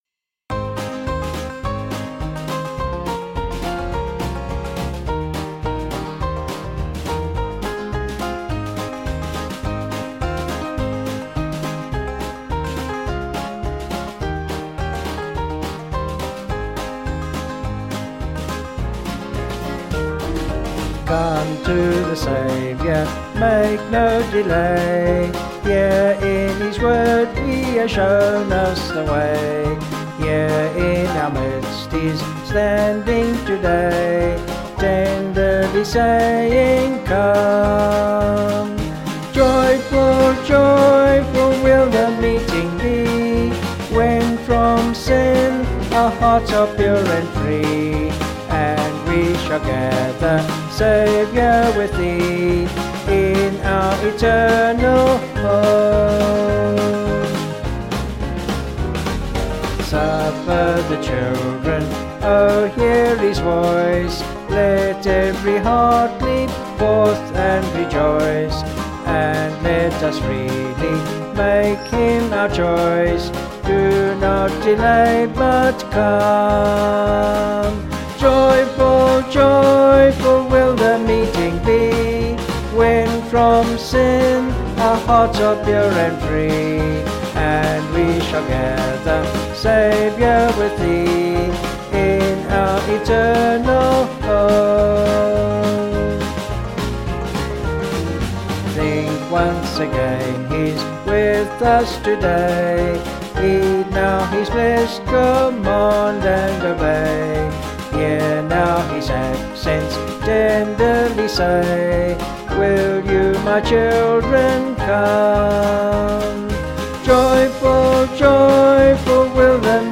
(BH)   3/Eb
Vocals and Band   265kb Sung Lyrics